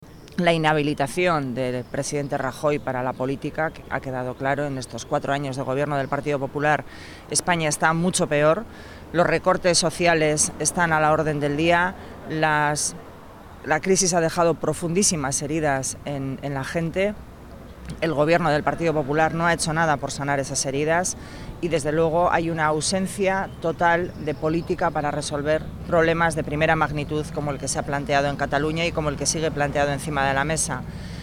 La Secretaria General del PSE-EE, Idoia Mendia, ha asegurado hoy en Rentería que el PP de Mariano Rajoy supone un peligro para el autogobierno vasco y ha explicado que, en las elecciones generales, los vascos van a poder elegir entre la imposición y los recortes del actual Gobierno y el cambio que plantea Pedro Sánchez.